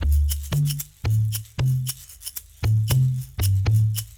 21 Samp Conga 01.wav